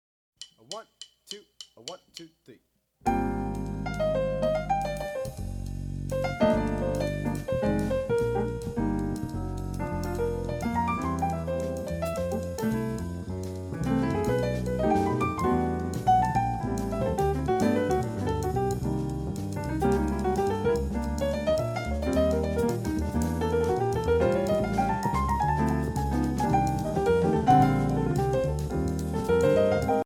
Voicing: DrumSet/CD